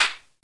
default_punch.ogg